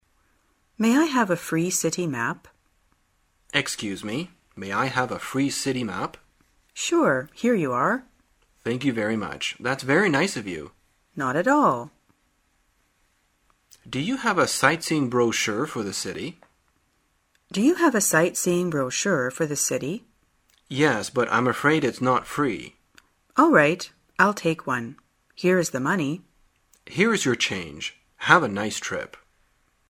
旅游口语情景对话 第4天:怎样索取地图等资料